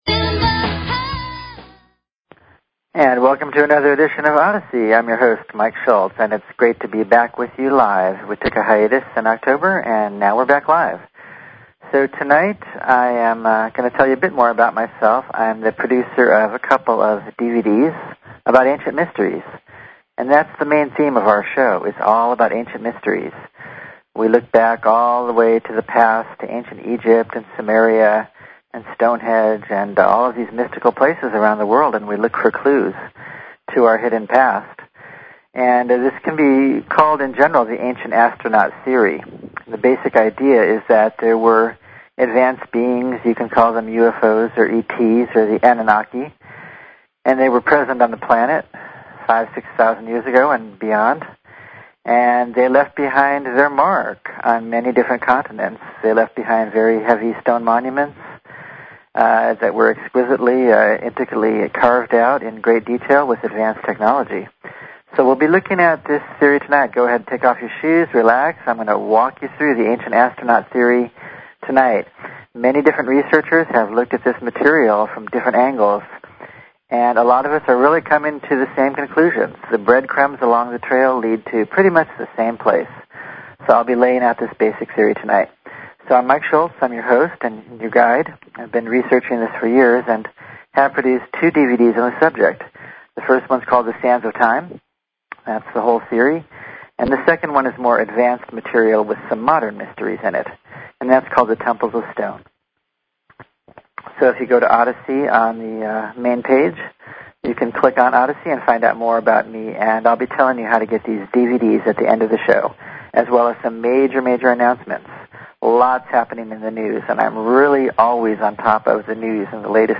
Talk Show Episode, Audio Podcast, Odyssey and Courtesy of BBS Radio on , show guests , about , categorized as